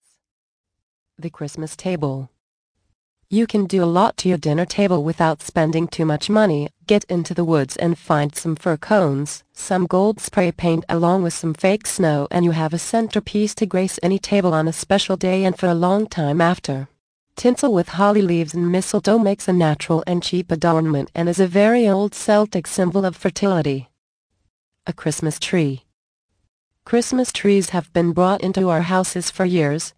The Magic of Christmas audio book. Vol. 6 of 10 - 69min